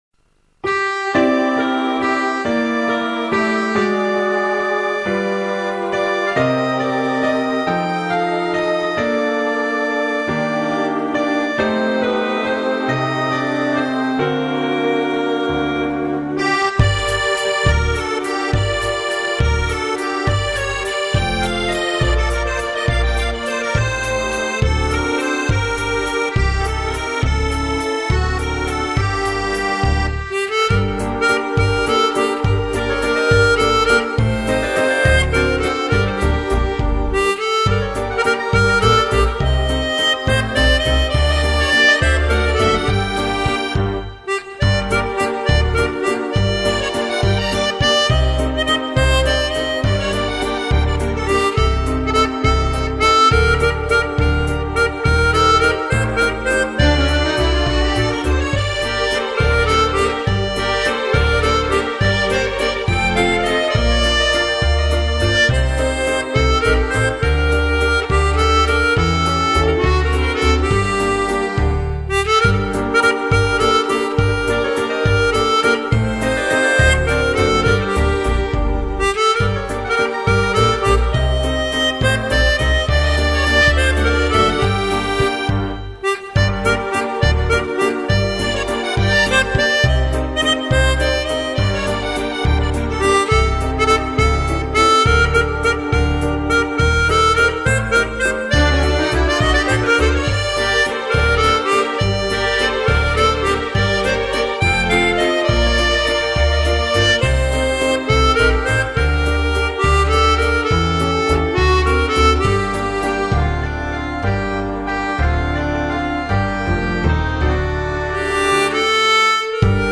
en sol mineur
Merci pour cette valse ,qui tourne bien ...